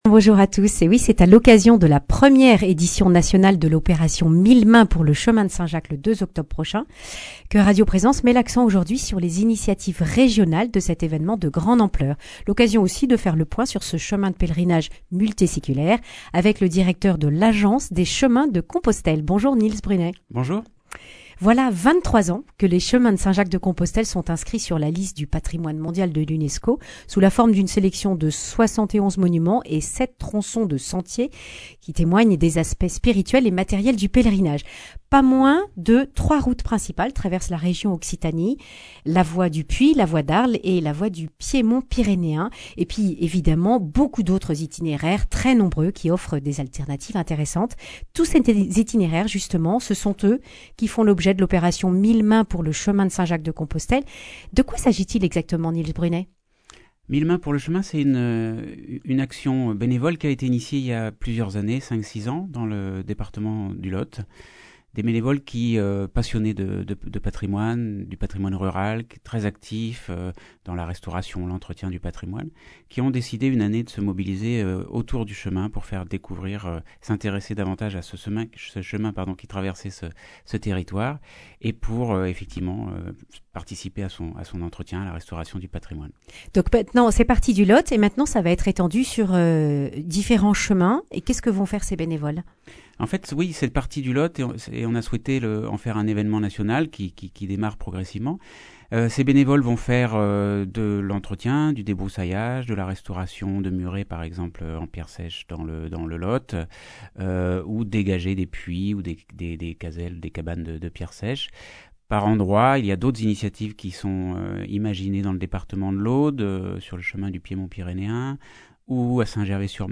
Accueil \ Emissions \ Information \ Régionale \ Le grand entretien \ Le point sur la saison 2020-2021 et l’année Jacquaire.